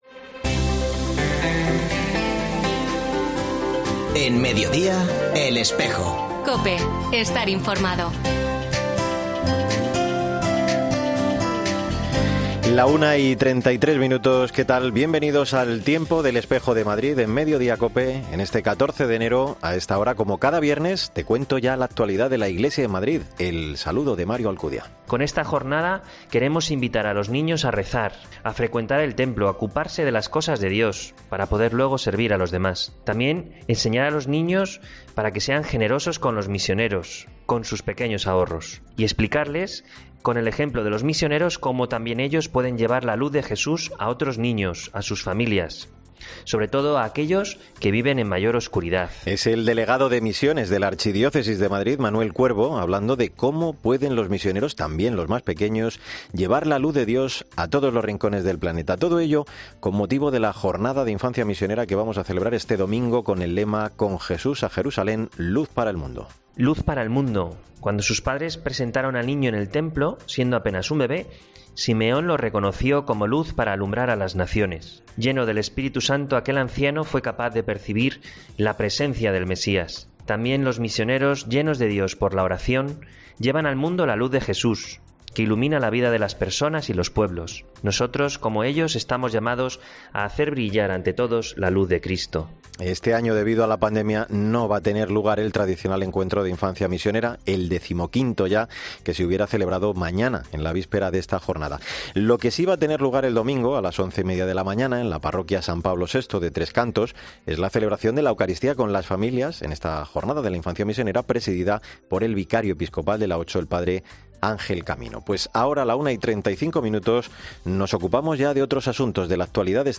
Jornada Infancia Misionera,festividad san Antón, proyecto Monasterio Hermanitas del Cordero,'Madrid Live Talent'.Entrevista monseñor Santos Montoya